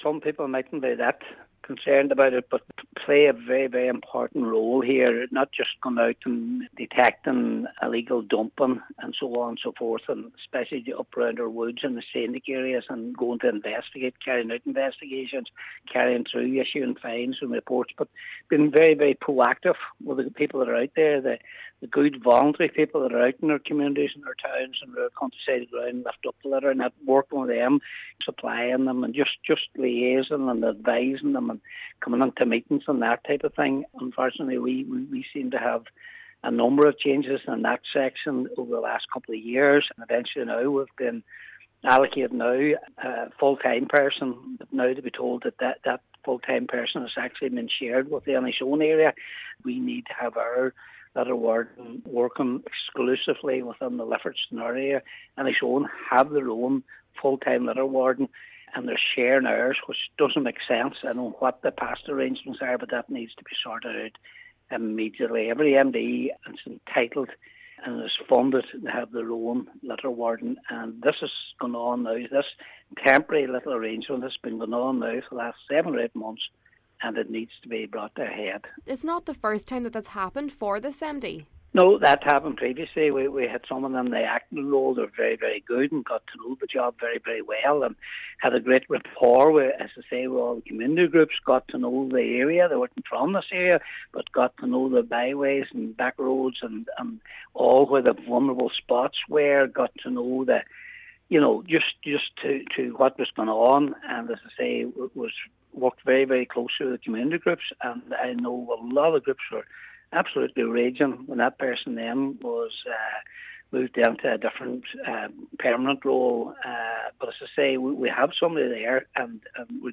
He says the geographical spread of the Lifford Stranorlar MD warrants a dedicated warden: